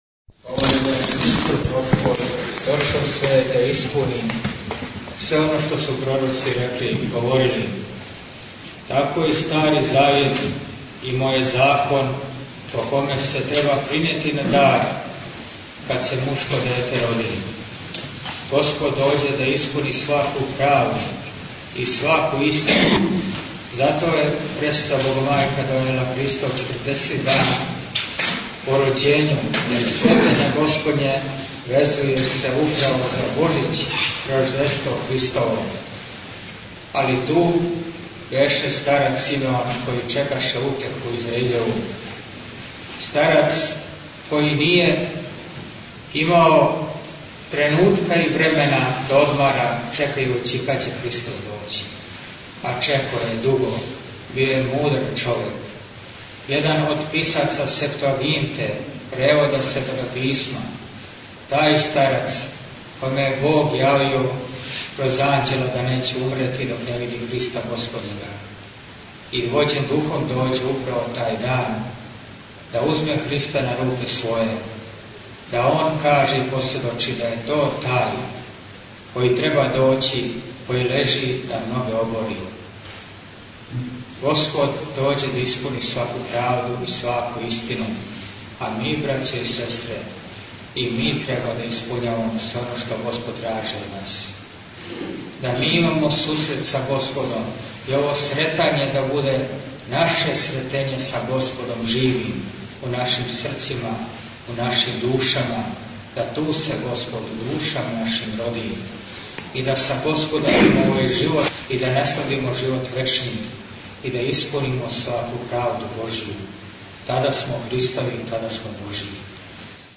У звучном запису чућете, једно за другим, најпре кратко слово у светоме храму
у парохијском дому